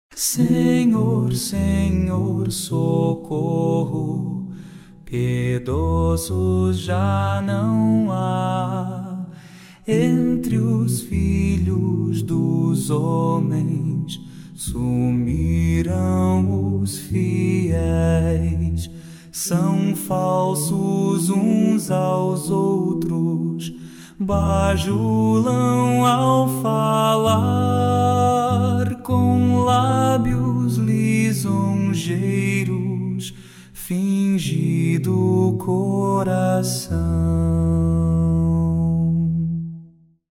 salmo_12B_cantado.mp3